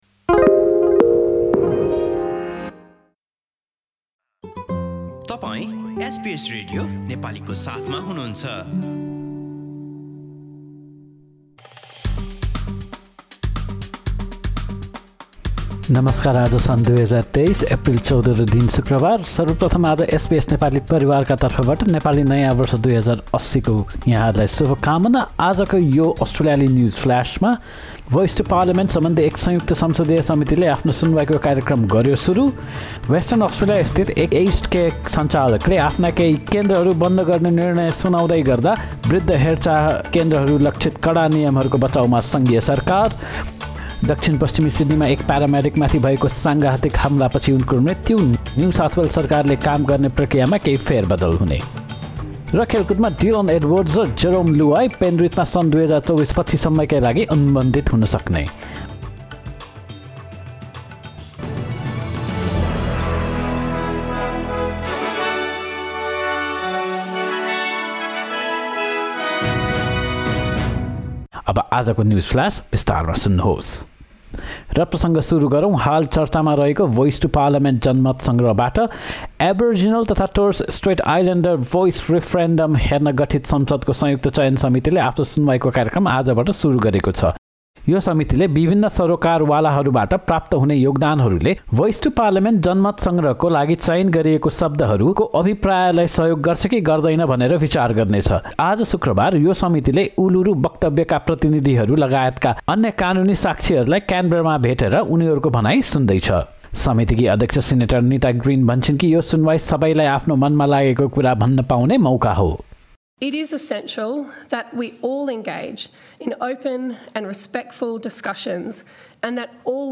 एसबीएस नेपाली अस्ट्रेलिया न्युजफ्लास: शुक्रवार, १४ एप्रिल २०२३